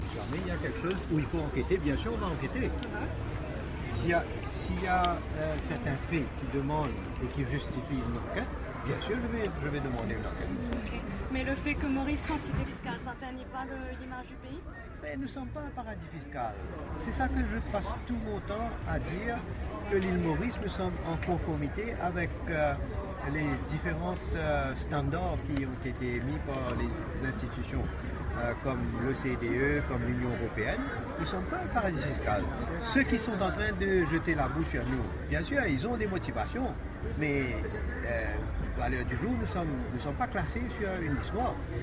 Le Premier ministre n’a pas échappé aux questions sur les Paradise Papers, ce mercredi 8 novembre, lors du salon des Petites et moyennes entreprises, à St-Pierre.